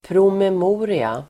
Uttal: [promem'o:ria]